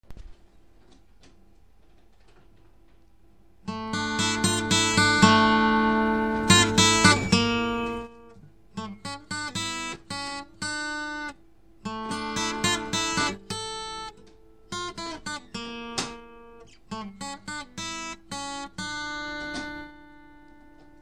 • Stop, w którym jest 85% miedzi i 15% cyny. Struny z owijką z takiego materiału łączą wspaniałą charakterystykę brzmienia strun z owijką typu 80/20, z większym sustain (dźwięk dłużej wybrzmiewa), co przypomina cechy strun fosforowo-brązowych